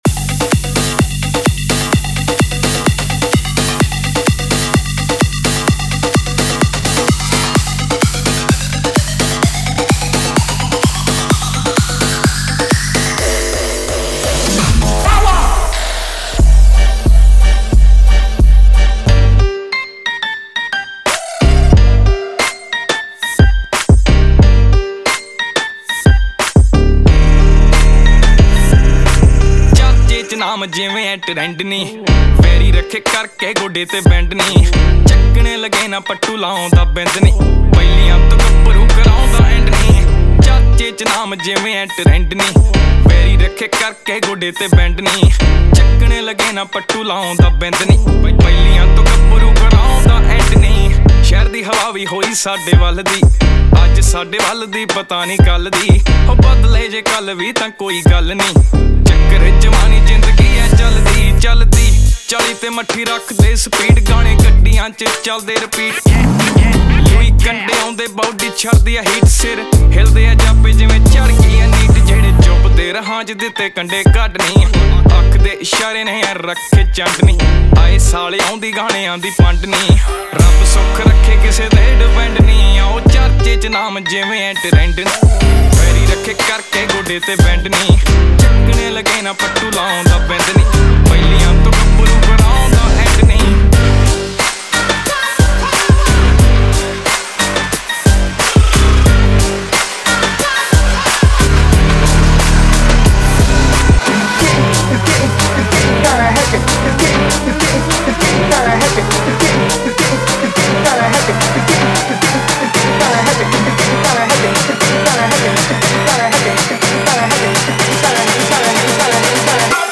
Category: Latest Dj Remix Song